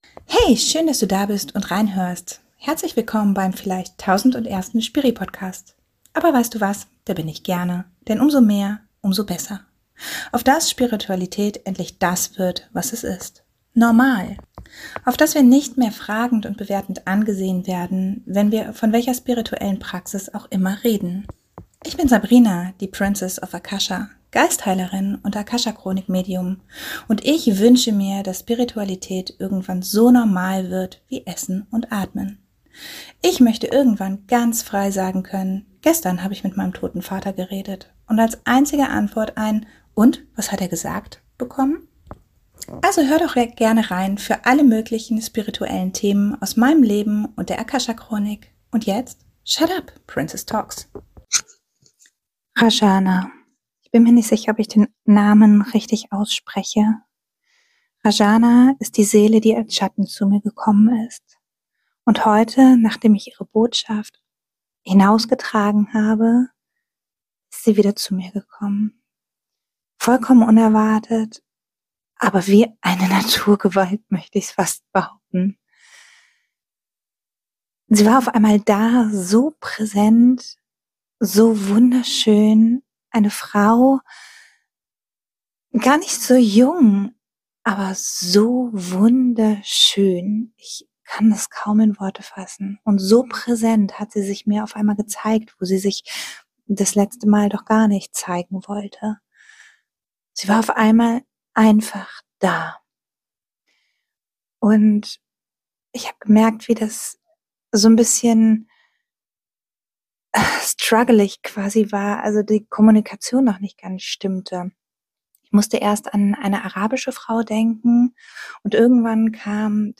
Ich mache heute mal einen Step-Over und teile mit dir dieses Live-Channeling. Unbearbeitet und ungeschönt - so wie es von dieser wundervollen Seele kam.